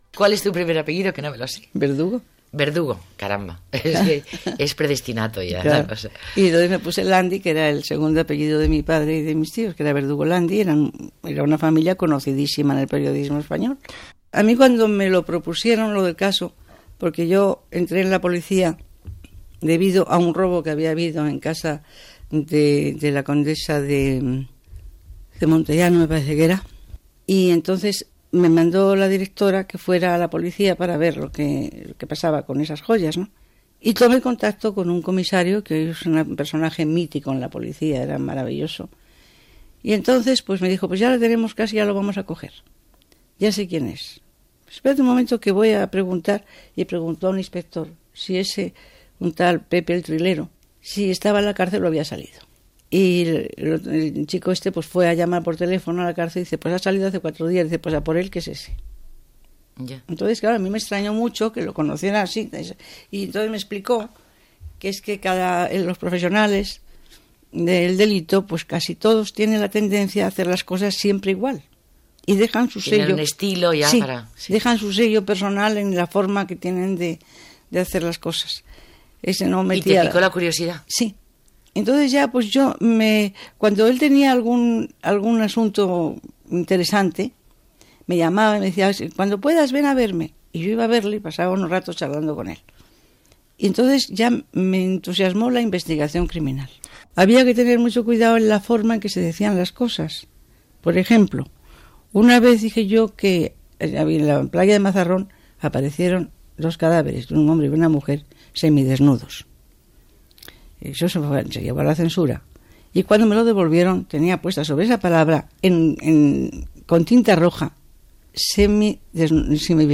Entrevista a la periodista de "El Caso" Margarita Landi (Margarita Verdugo Díez)